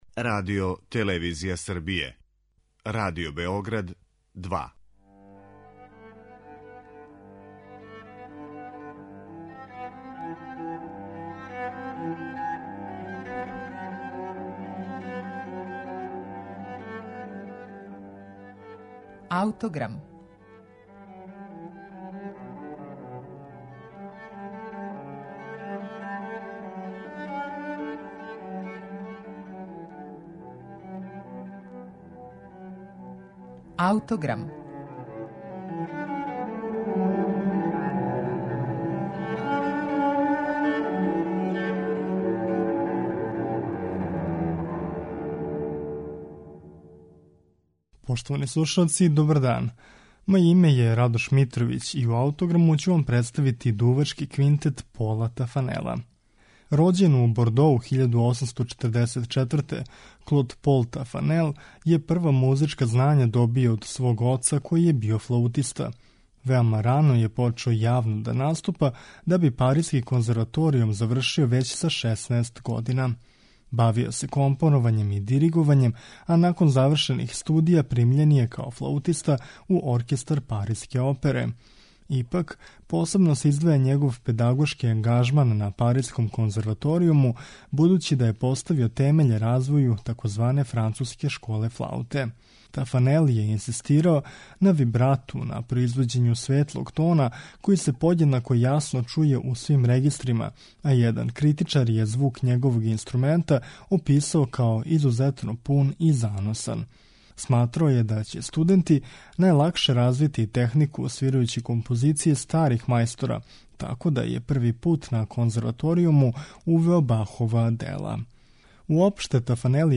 Дувачки квинтет